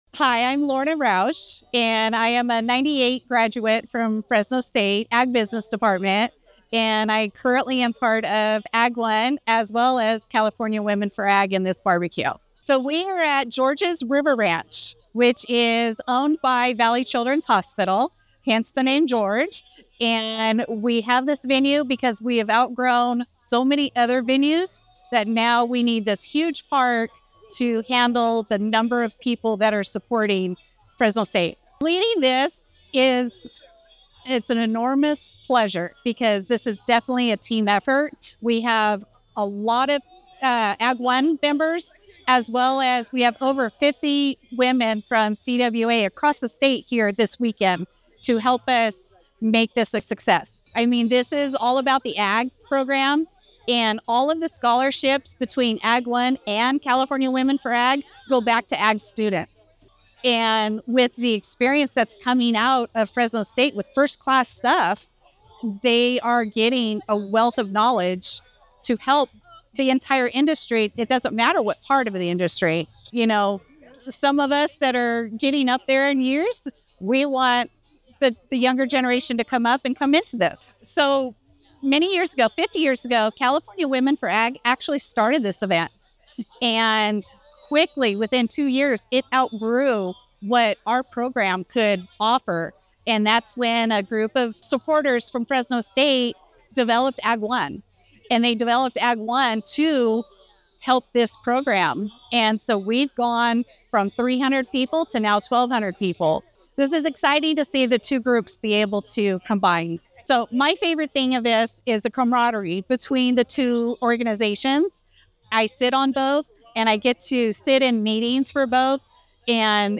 The Ag Center – News Report for Thursday, September 18th